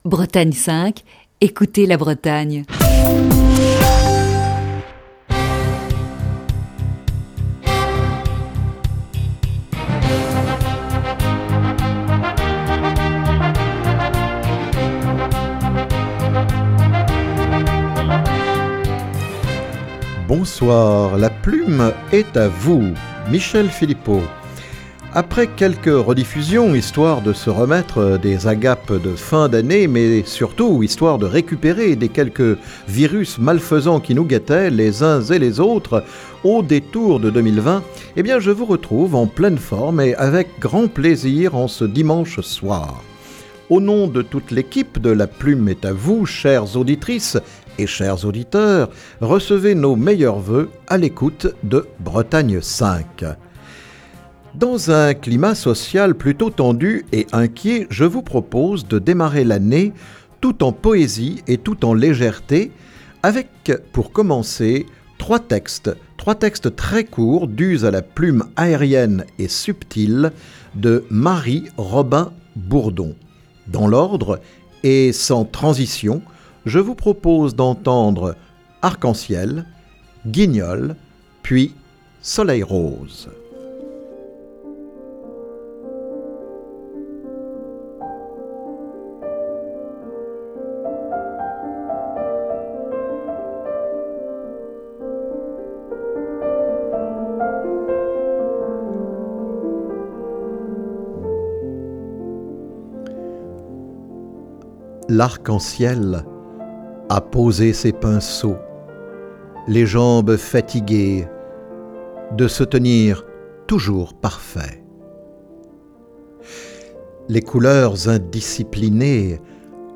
en direct